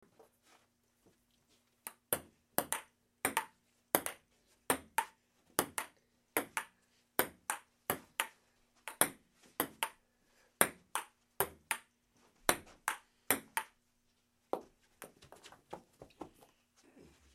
ping-pong-sample.mp3